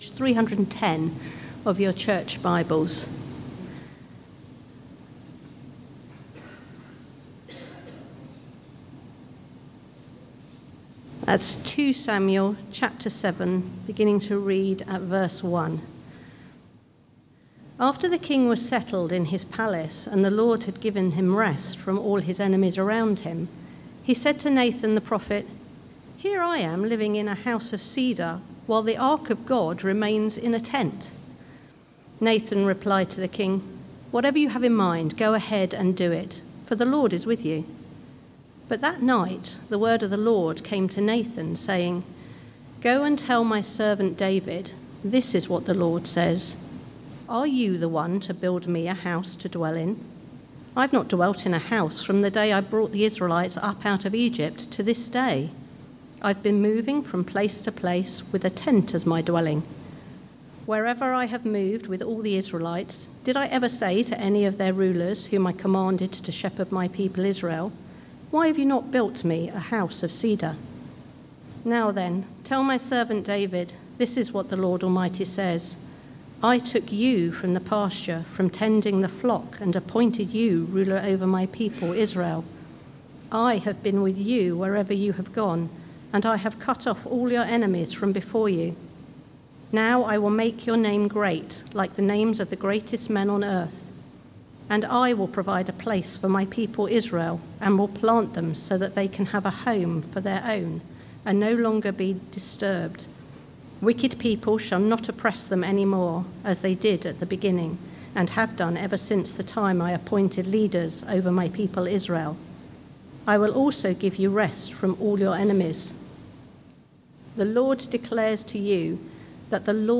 Sermons – Dagenham Parish Church
Luke 1 Service Type: Sunday Morning